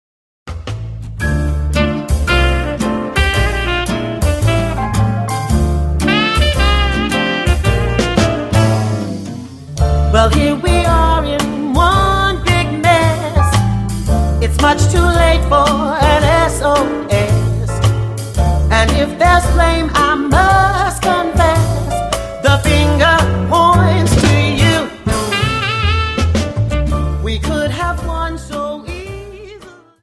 Slowfox 29 Song